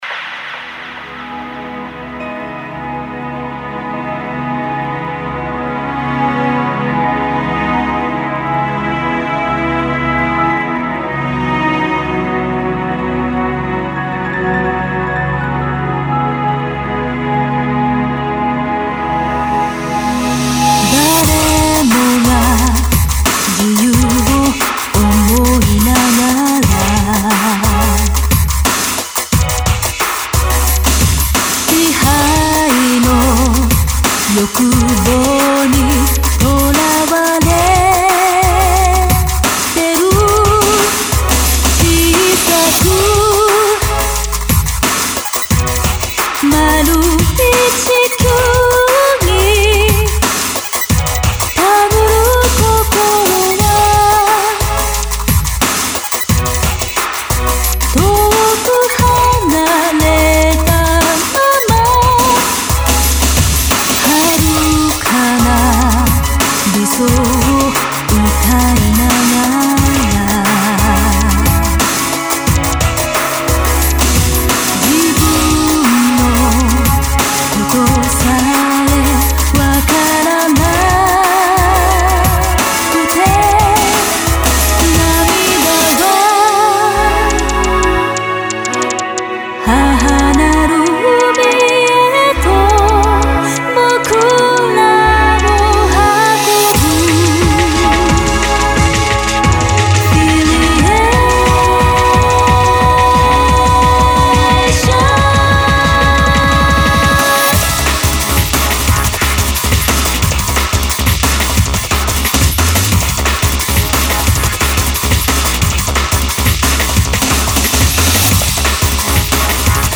유튜브에 있긴 하던데 음질이 너무 심하게 그지같아서 그냥 제가 CD에서 뽑았습니다[…]